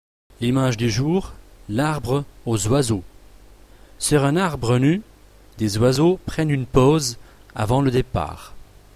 Podcast_arbre_oiseaux.mp3 (142.46 Ko)
Diapo ci-dessous de la chanson des oiseaux